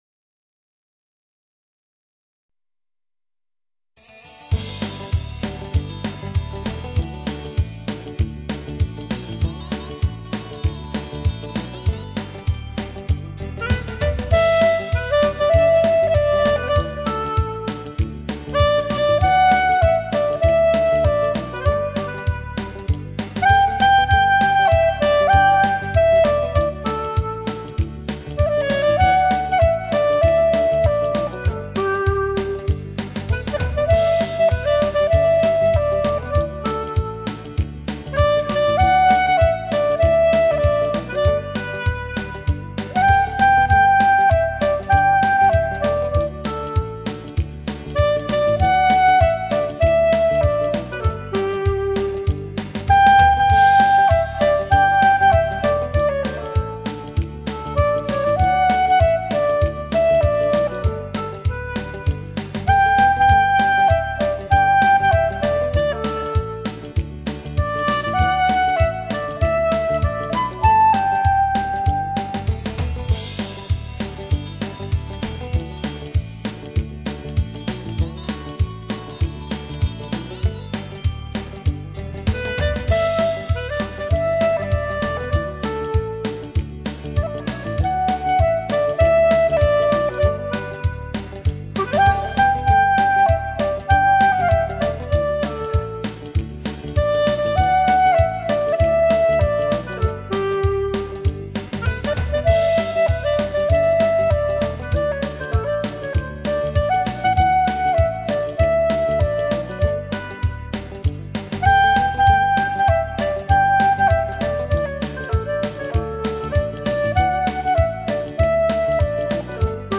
0165-单簧管名曲什锦菜.mp3